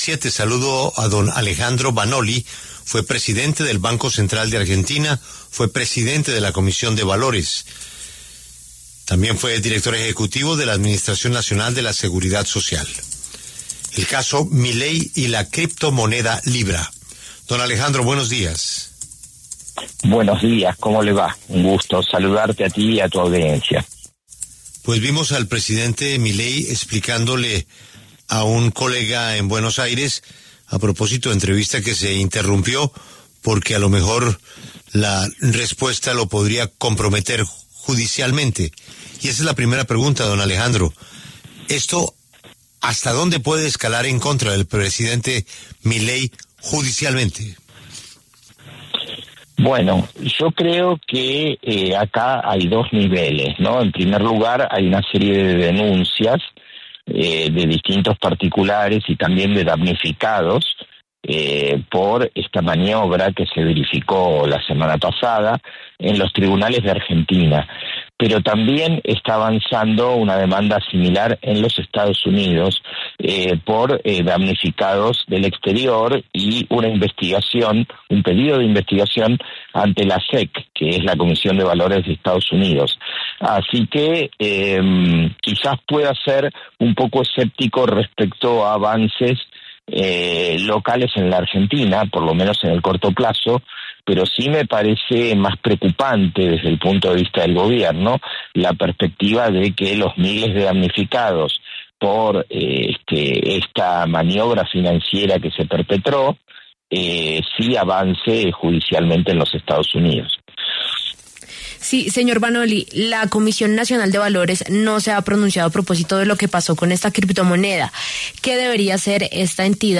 El expresidente del Banco Central de Argentina habló sobre los efectos que tendrá para el país la criptomoneda $LIBRA.